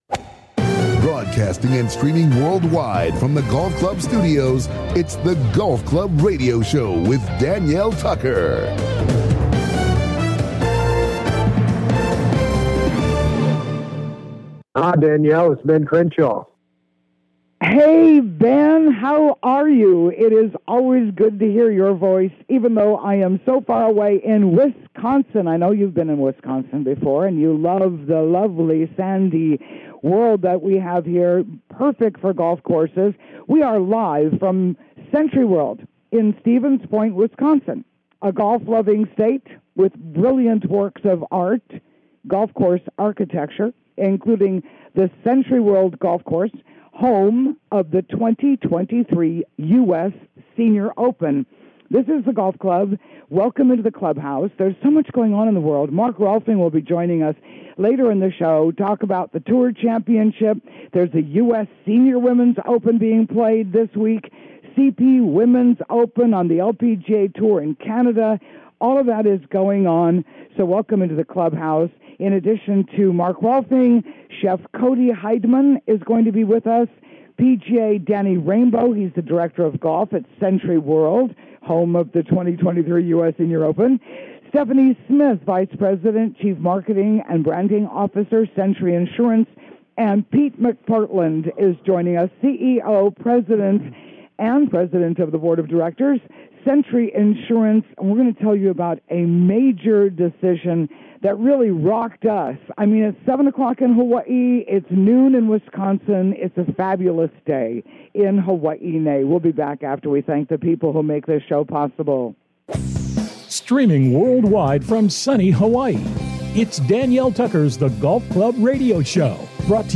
LIVE FROM SENTRYWORLD IN STEVENS POINT, WISCONSIN